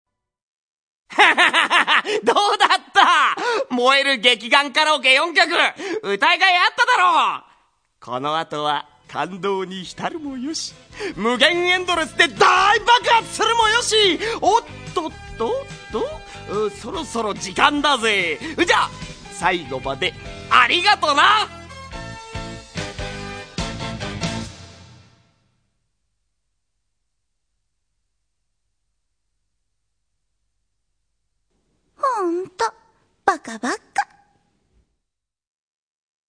Seki Tomokazu-sanGai Daigohji's seiyuu (or voice actor) is none other than Seki Tomokazu, my third favorite seiyuu.
These sounds are from the Gekiganger Karaoke section of the soundtrack where Gai introduces each song for the karaoke section.
Gai's Hotblooded Talk #5 - Gai says goodbye and ends the karaoke section.